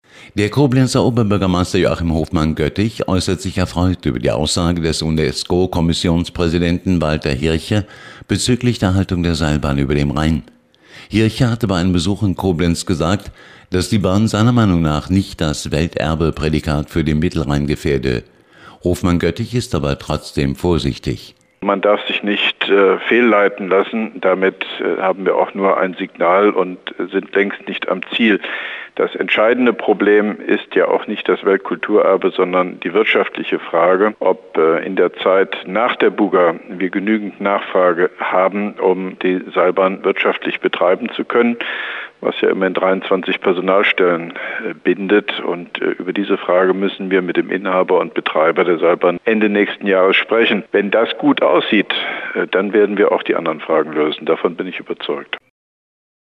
Antenne Koblenz 98,0, Nachrichten 8.30 Uhr, 07.06.2011